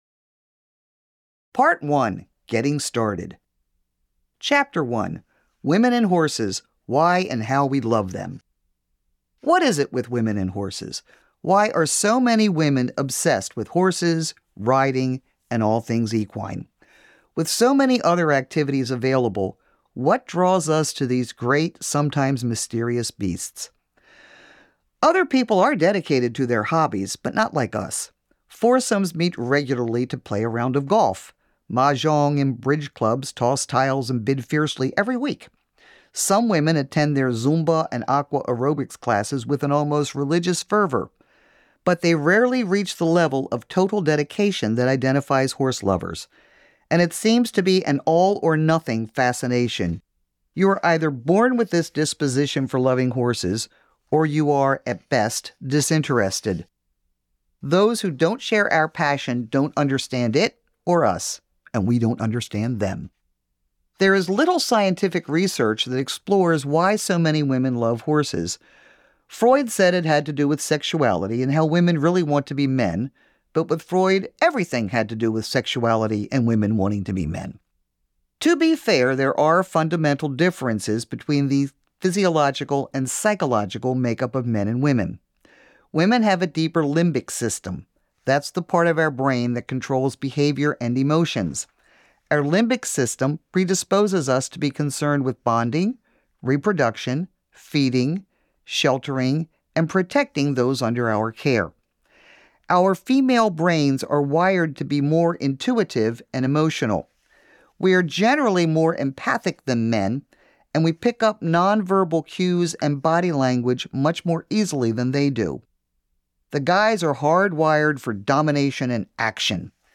• Audiobook • 7 hrs, 52 mins